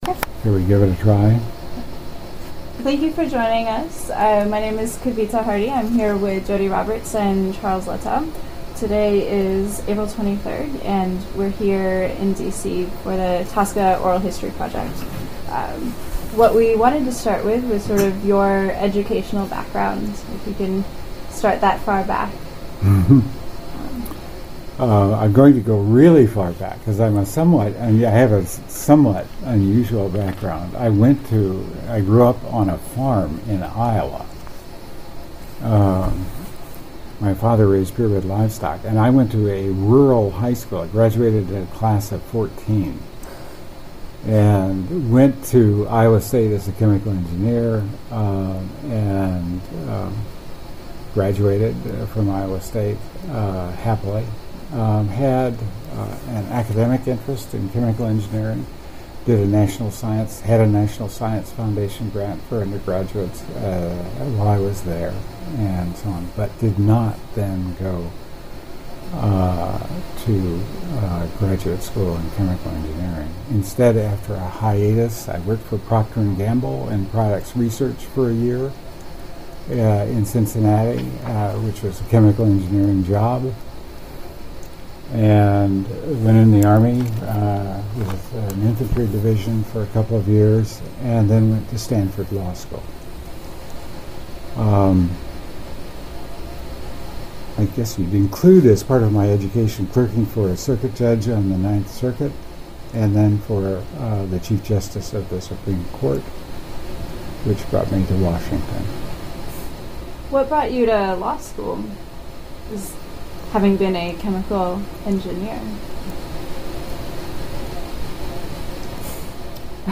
Oral history interview of the toxic substances control act from the perspective of Charles F. Lettow